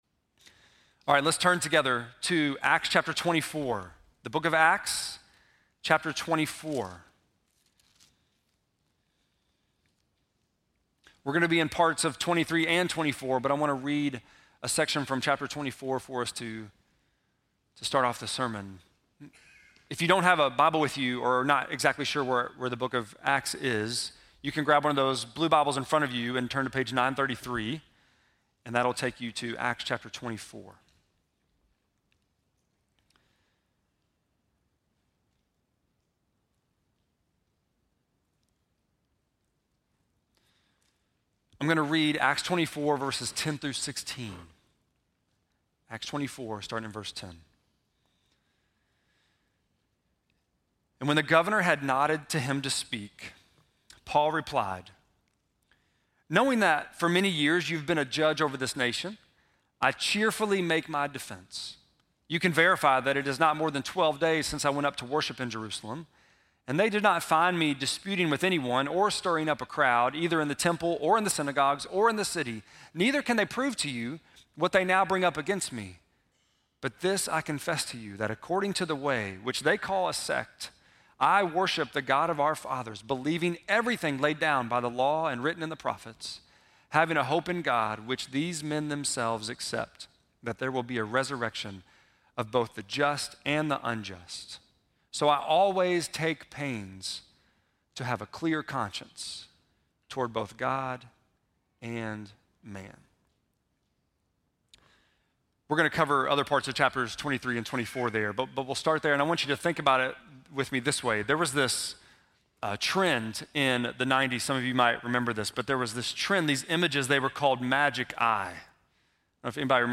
11.10-sermon.mp3